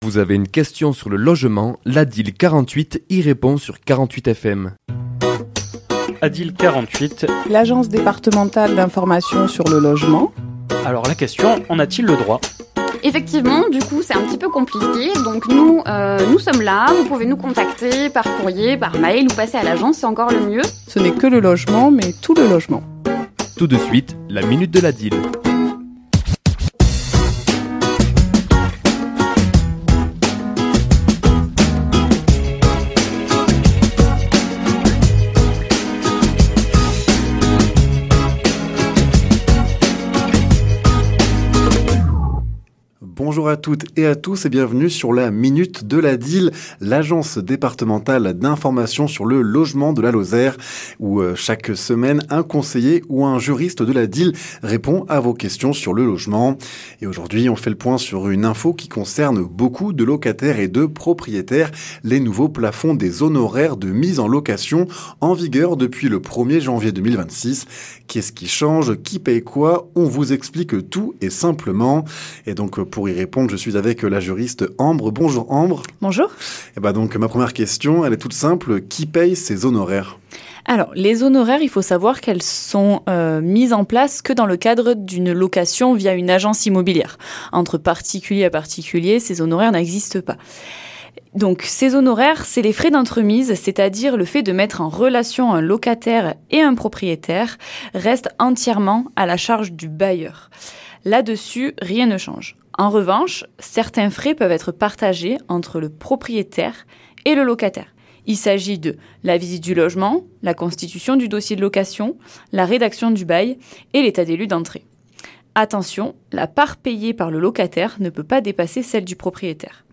ChroniquesLa minute de l'ADIL
Chronique diffusée le mardi 3 mars à 11h et 17h10